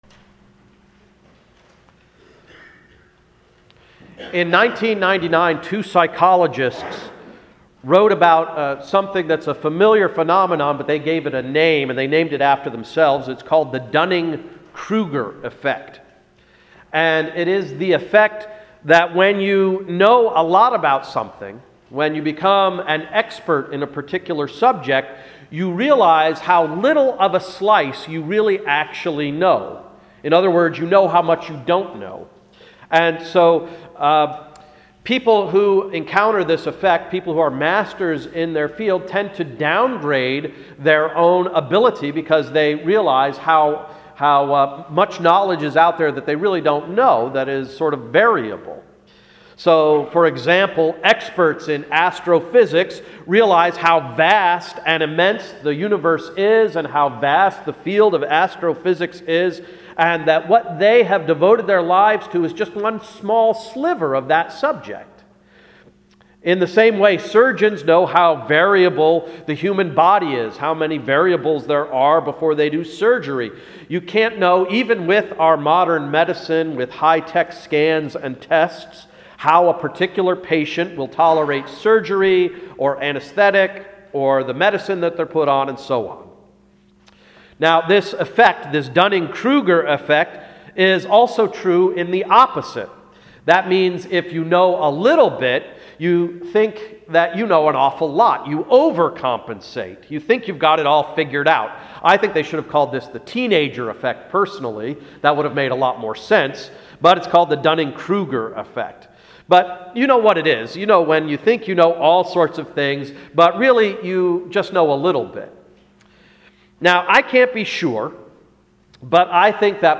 Sermon of February 20th, 2011–“Paul’s Plan for Progress”